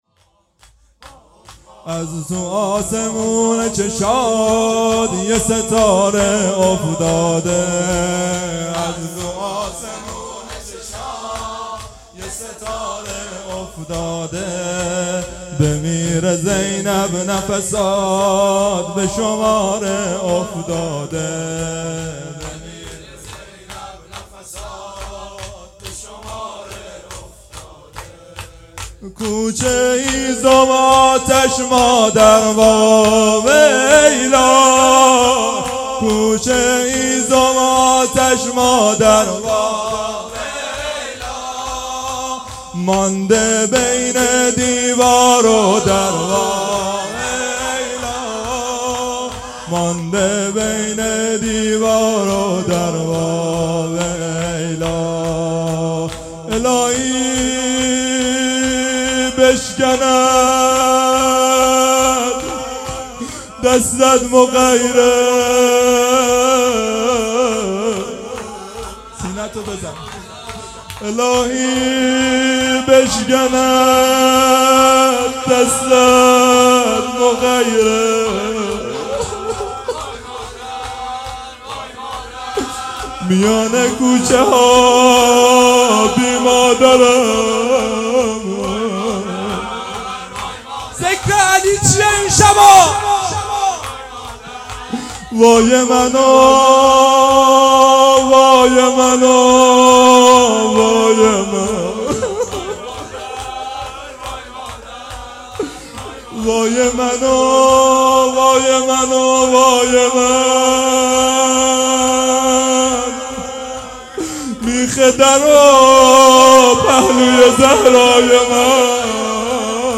از تو اسمون چشات یه ستاره افتاده _ شور و روضه
اقامه عزای شهادت حضرت زهرا سلام الله علیها _ دهه دوم فاطمیه _ شب اول